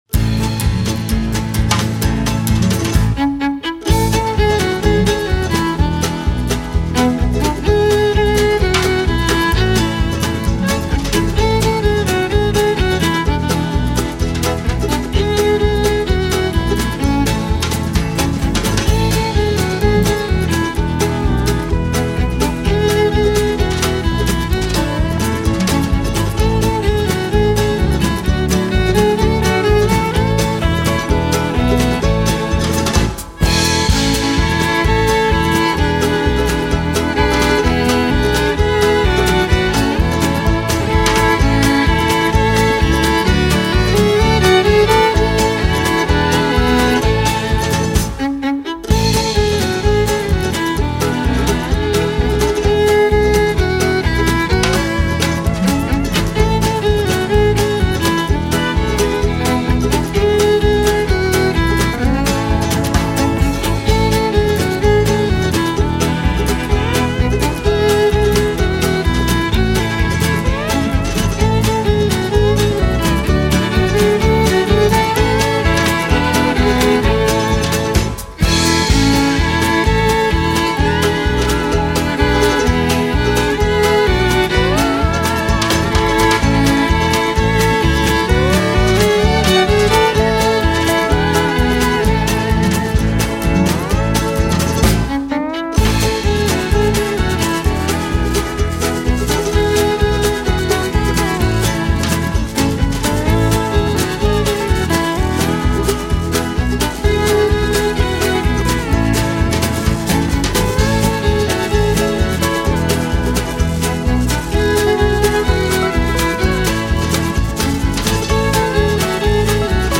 Старая мелодия в стиле кантри.